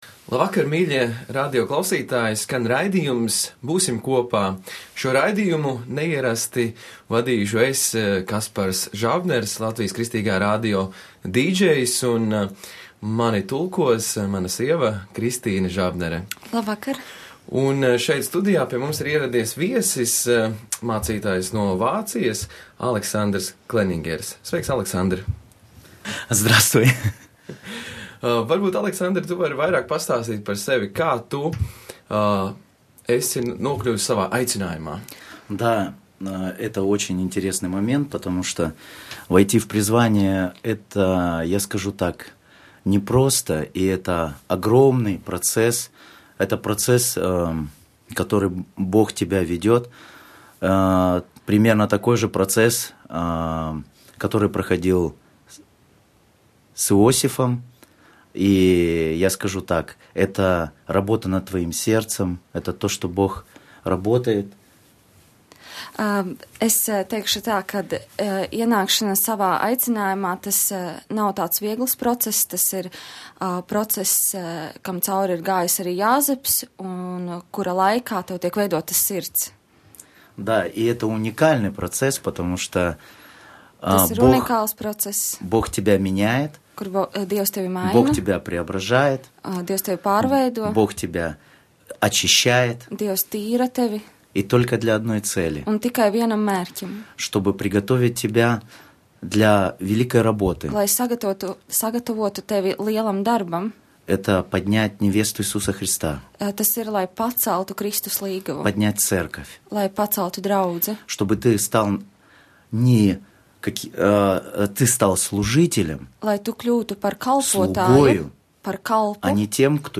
raidījuma viesis